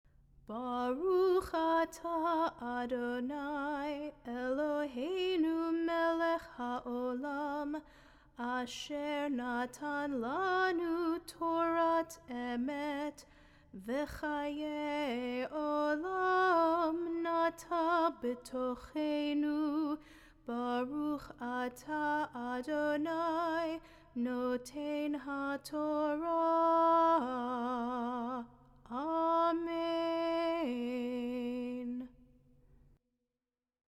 Blessing after Torah Reading.mp3